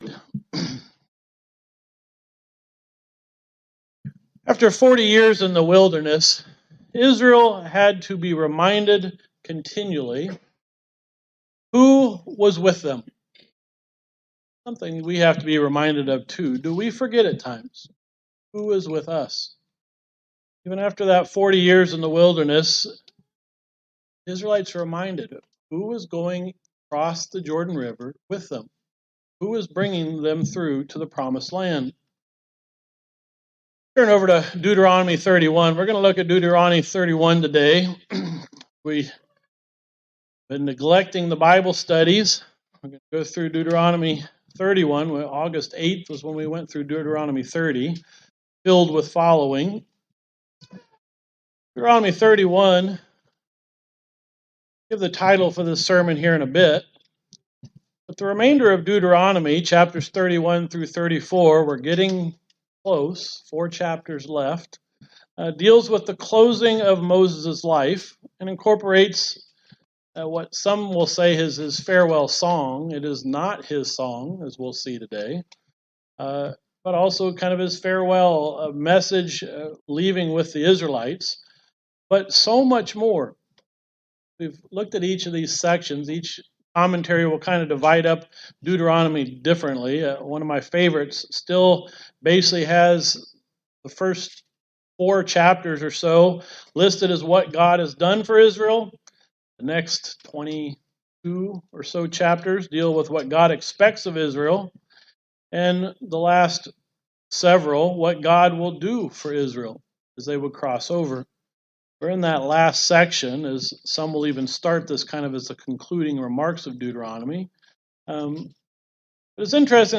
Sermons
Given in Elkhart, IN Northwest Indiana